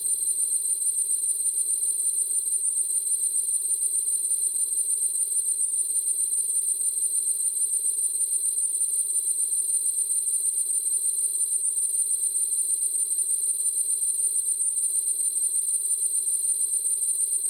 electric-cicadas04
ambient bed bell chimes cicadas digital ding effect sound effect free sound royalty free Sound Effects